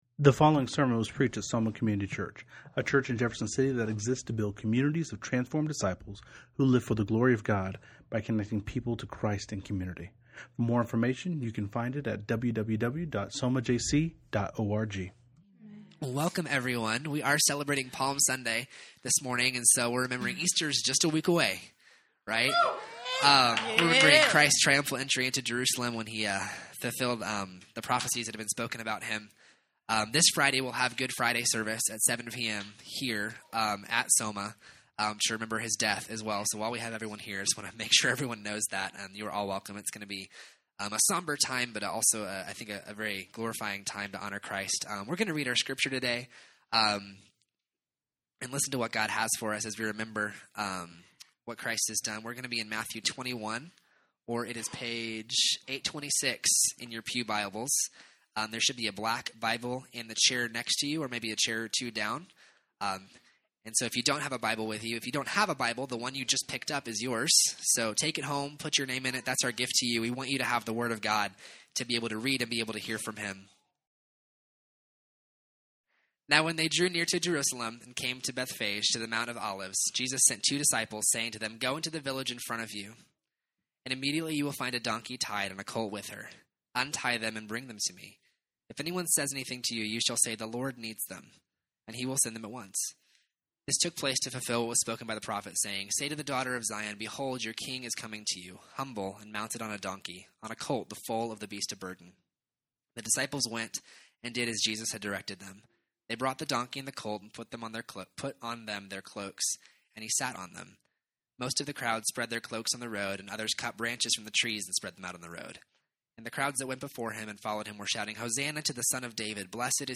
Topics: Sermon Audio « Developing a Disciple’s Attitude Why was the stone rolled away?